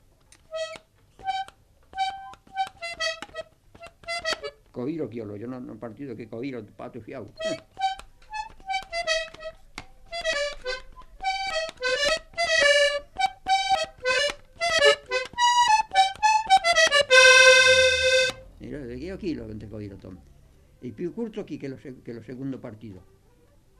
Genre : morceau instrumental
Instrument de musique : accordéon diatonique
Danse : quadrille